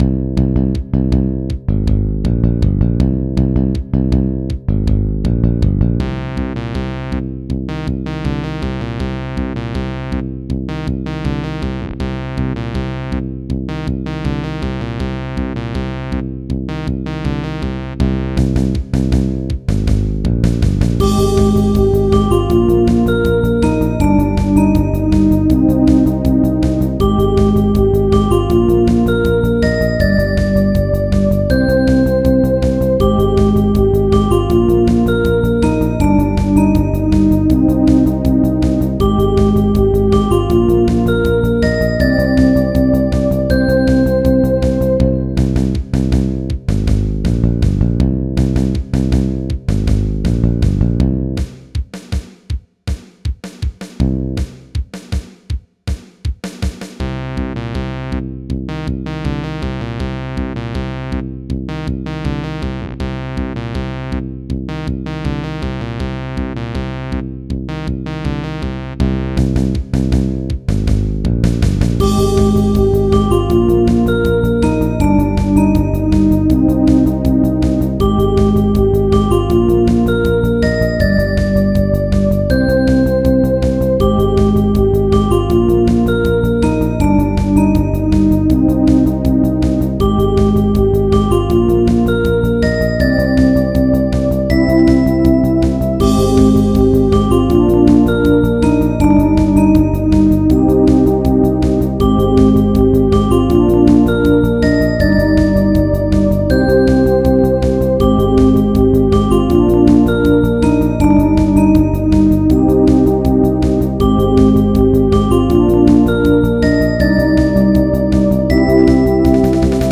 シンセサイザーを多用したスタイリッシュなBGM。
速い、シンセサイザー、疾走感